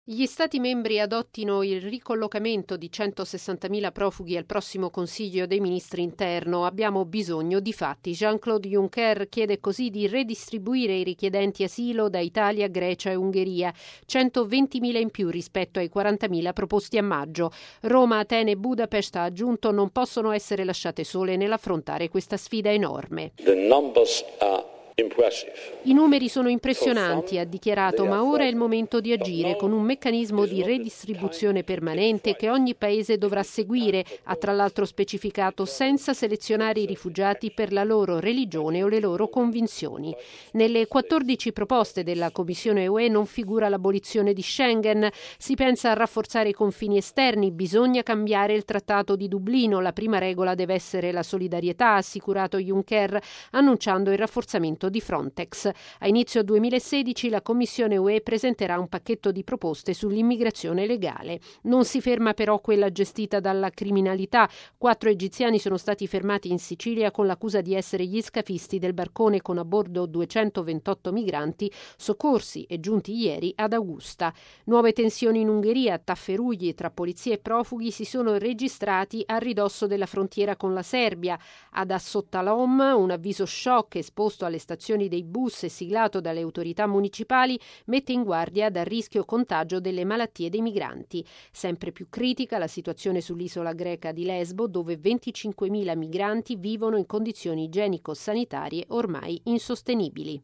Intervista con Martin Schulz